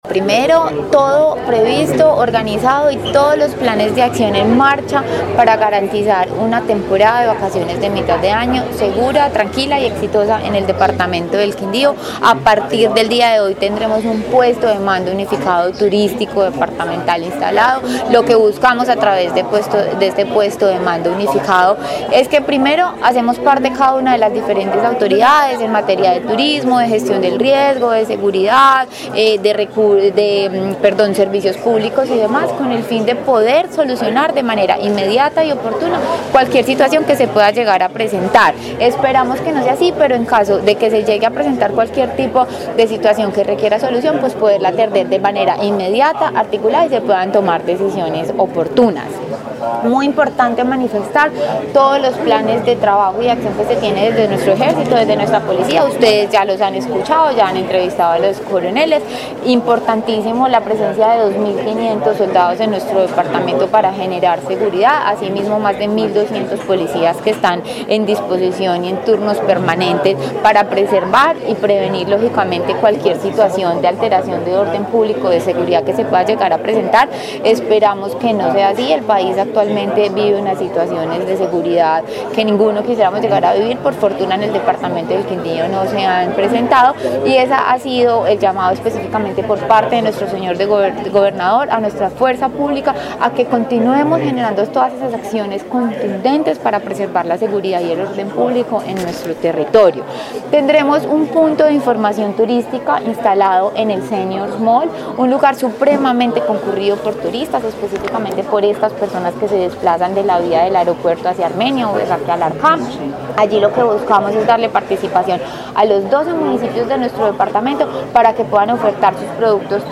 Juana Camila Gómez, secretaria de turismo del Quindío